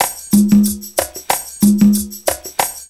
PERCULOOP -L.wav